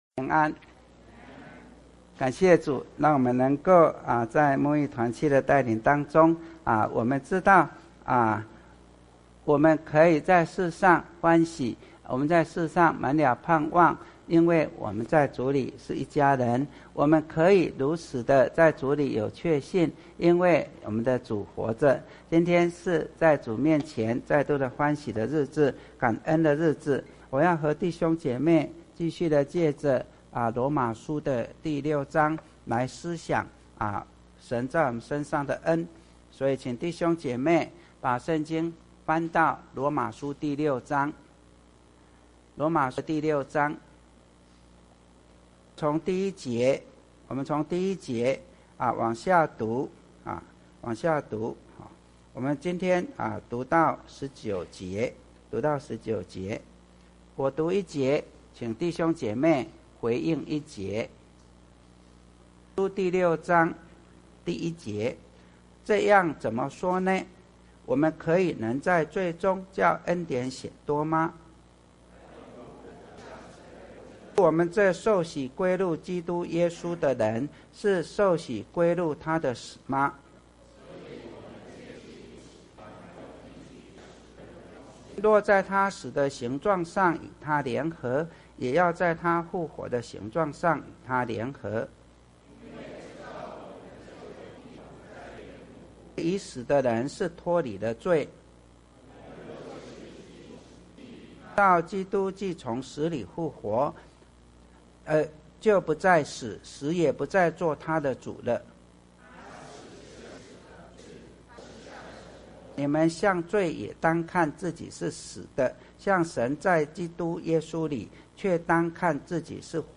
Posted in 主日信息